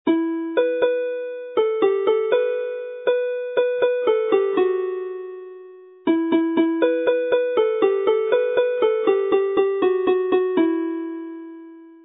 canu + offeryn